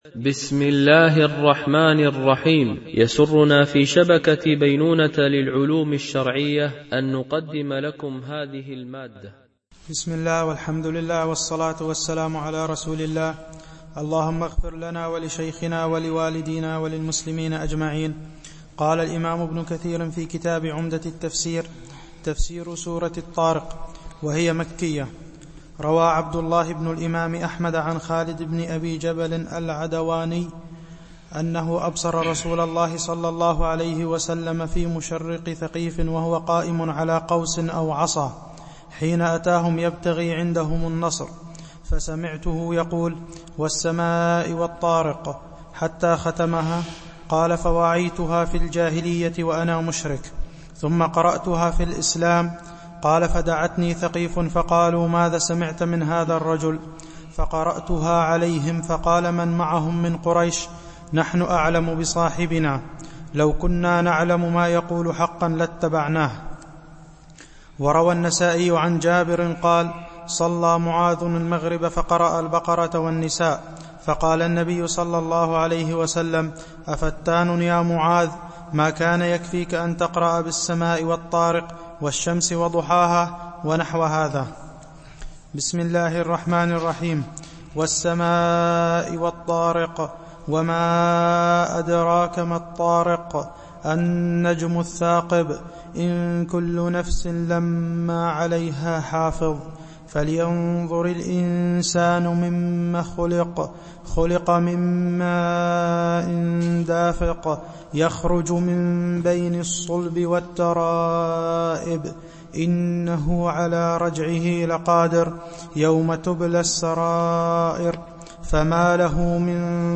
شرح مختصر تفسير ابن كثير(عمدة التفسير) الدرس 52 (سورة الطارق والأعلى)